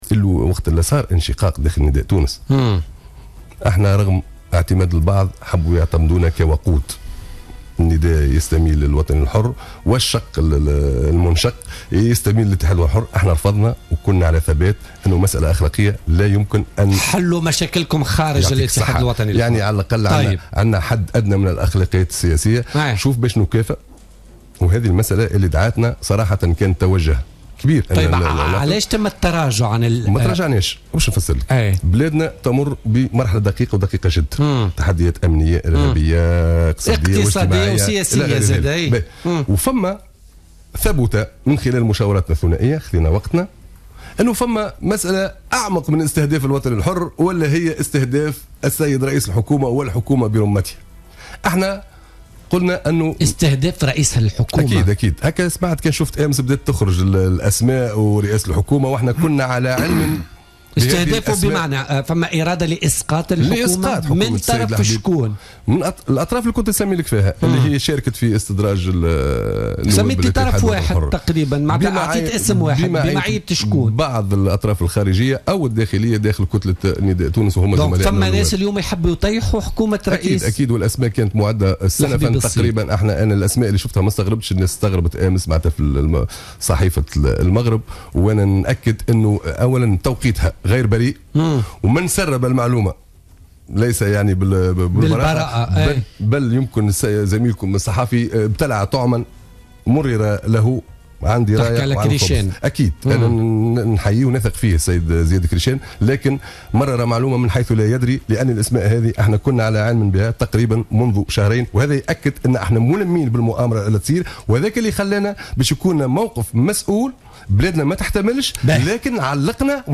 قال طارق الفتيتي النائب بمجلس نواب الشعب عن الاتحاد الوطني الحر والمستقيل من رئاسة كتلة حزبه الاثنين الفارط خلال حضوره ضيفا في برنامج بوليتكا لليوم الأربعاء 18 ماي 2016 إن "مؤامرة " قد حيكت ضد حكومة الحبيب الصيد لإسقاطها.